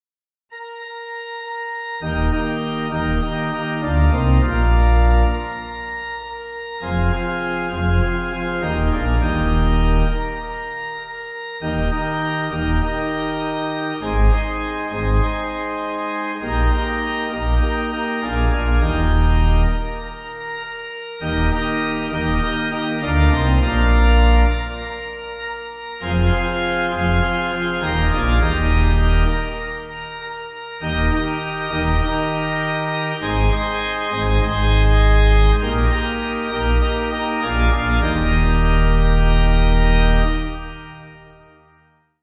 Organ
(CM)   2/Eb